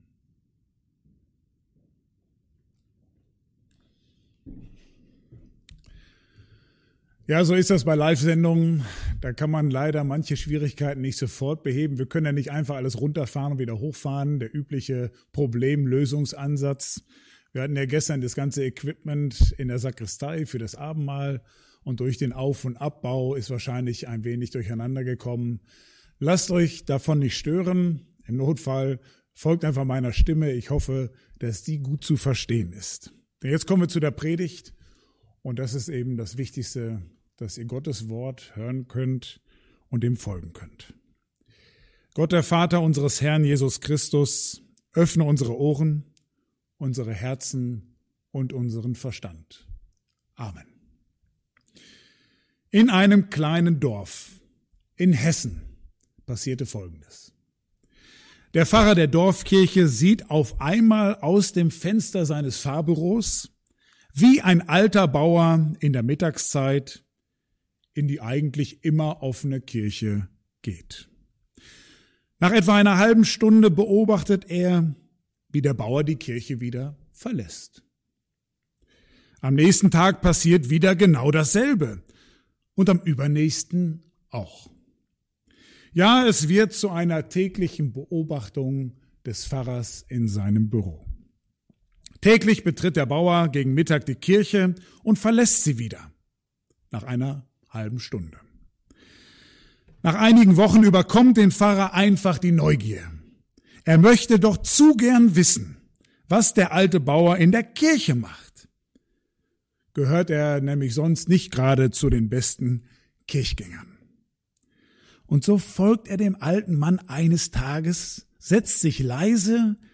Korinther 5,14b-21 Dienstart: Gottesdienst « Wenn ich (wieder) gesund bin Neugierig auf ein Leben voller unerwarteter und erwarteter Überraschungen?